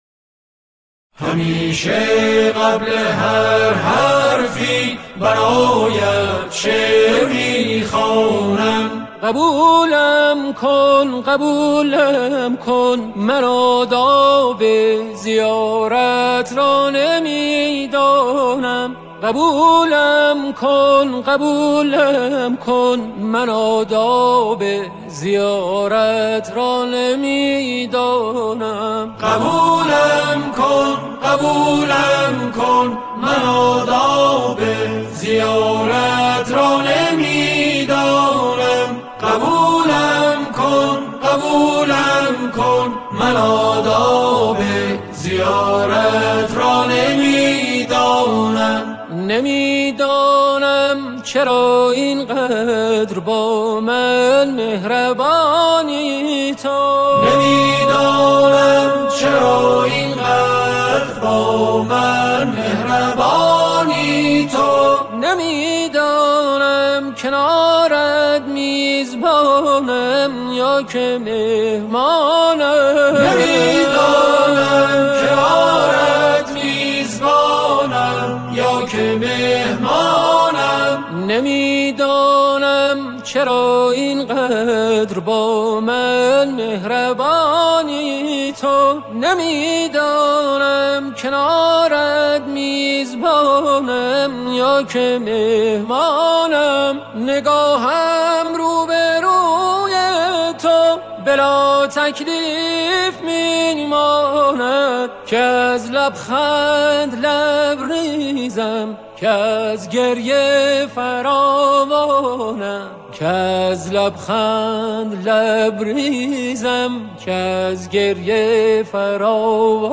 آکاپلا